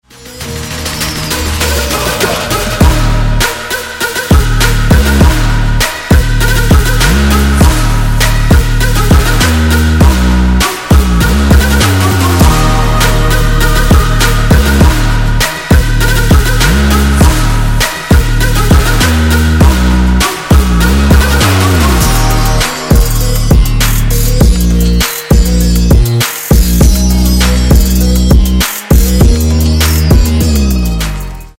Громкие Рингтоны С Басами » # Рингтоны Без Слов
Рингтоны Электроника